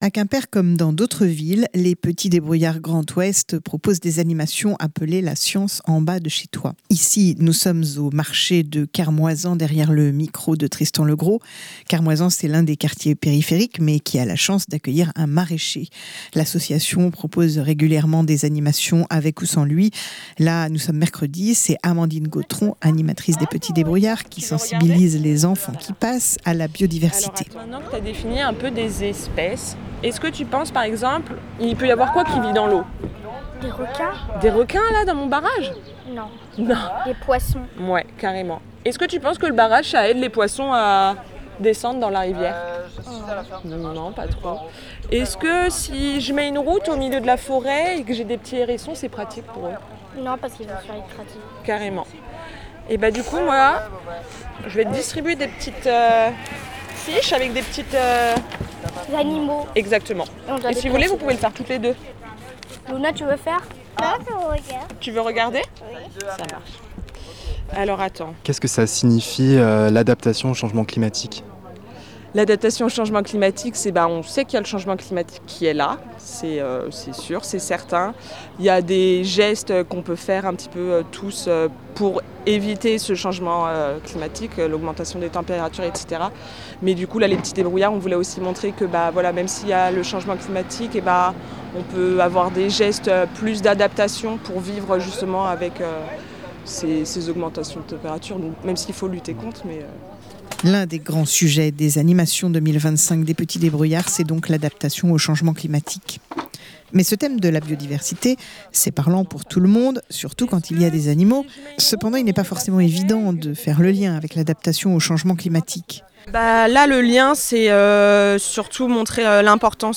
LEMRUB-Petits-debrouillards-adaptation-climat-Quimper-biodiversite-reportage.mp3